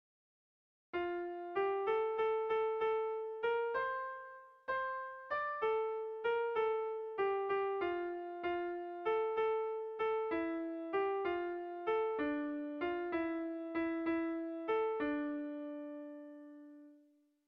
Bertso melodies - View details   To know more about this section
Irrizkoa
8A / 8B / 10A / 8B
ABDE